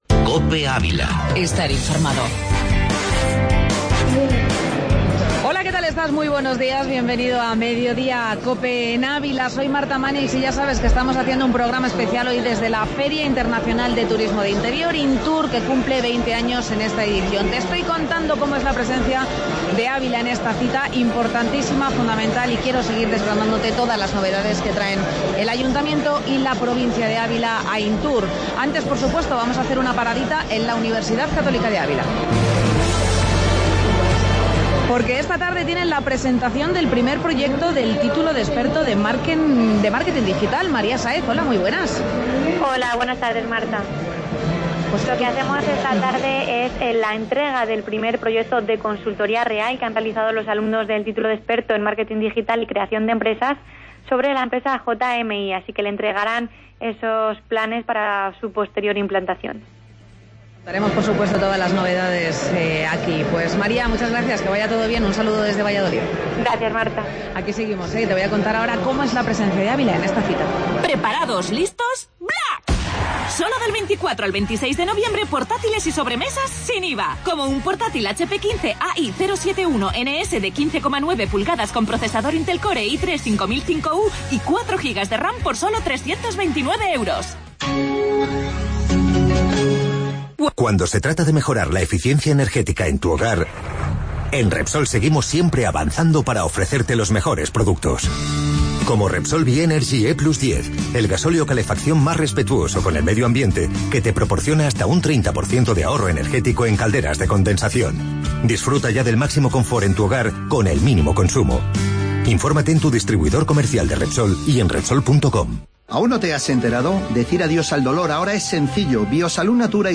AUDIO: Programa especial desde Intur 2016